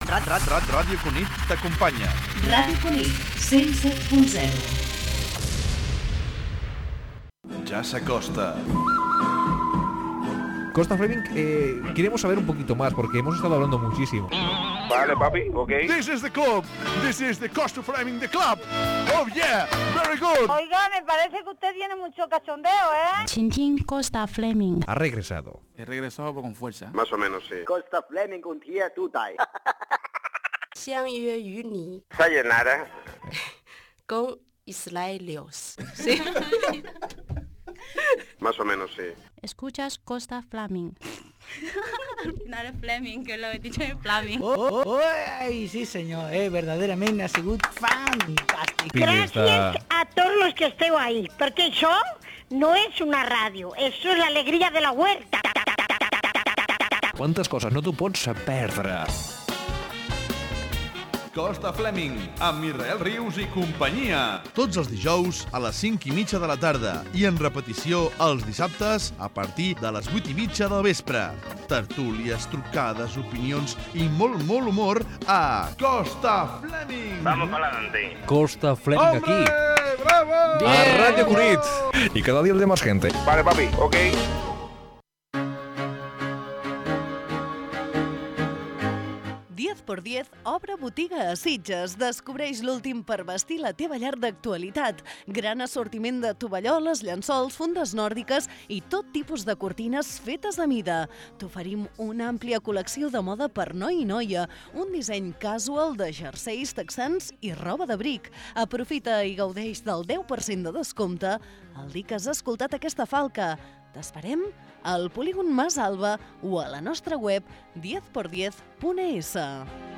Gènere radiofònic Entreteniment
Banda FM